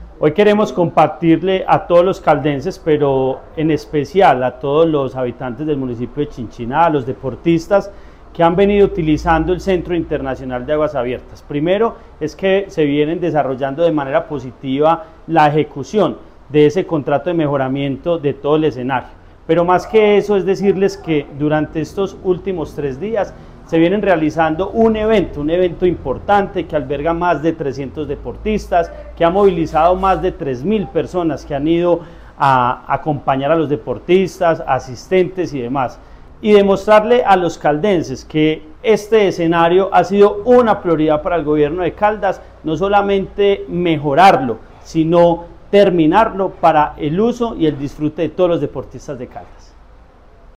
Andrés Duque Osorio, secretario de Deporte, Recreación y Actividad Física de Caldas.